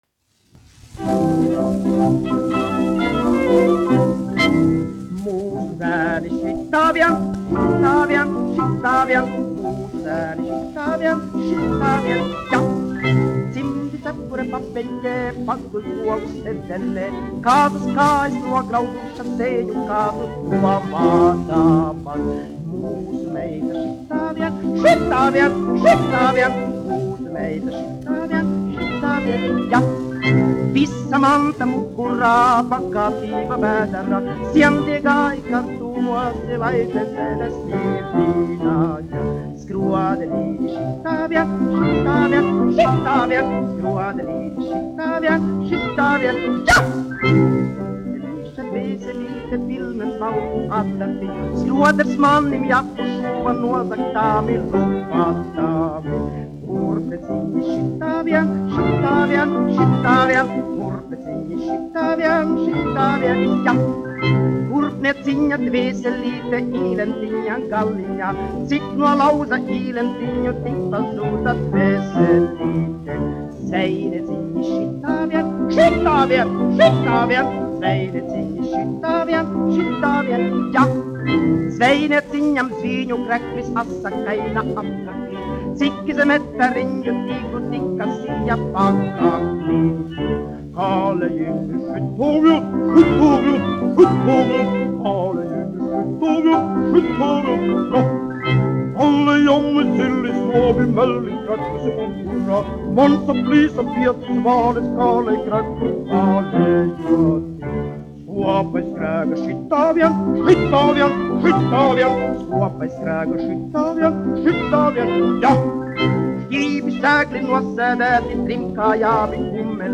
1 skpl. : analogs, 78 apgr/min, mono ; 25 cm
Humoristiskās dziesmas
Skaņuplate
Latvijas vēsturiskie šellaka skaņuplašu ieraksti (Kolekcija)